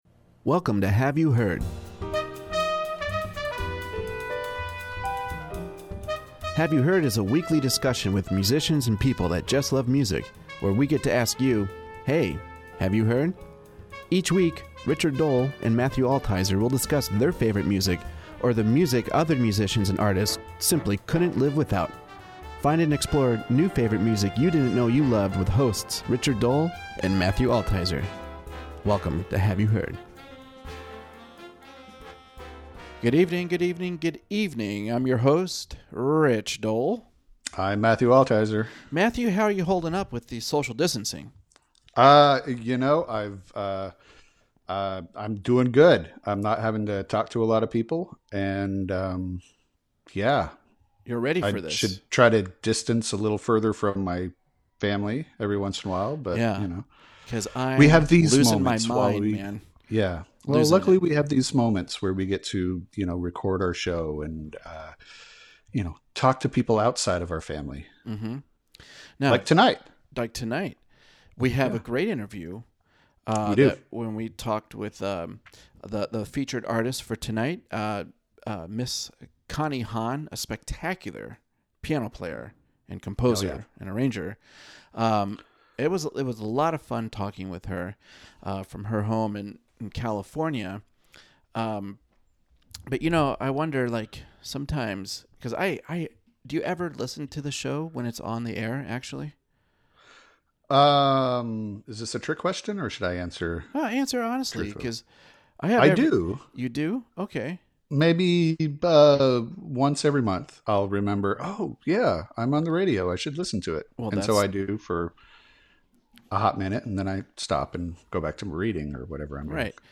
All in all it was a very engaging and enlightening conversation.